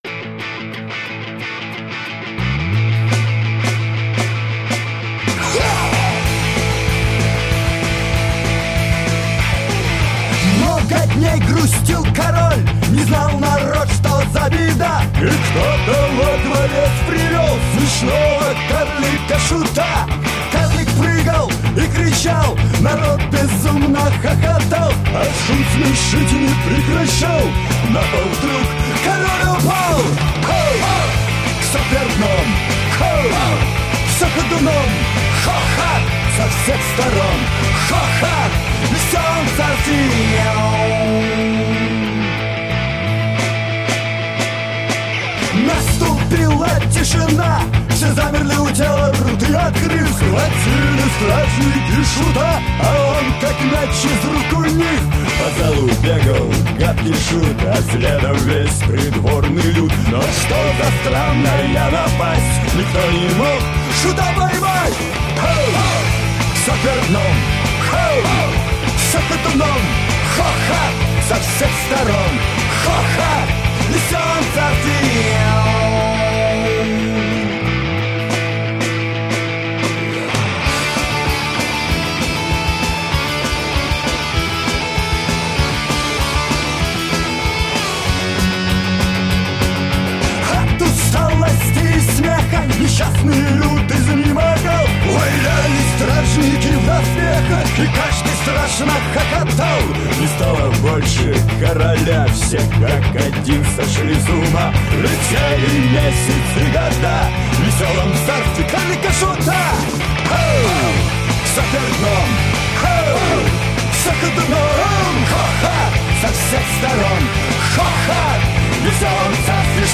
Рок
Жанр: Жанры / Рок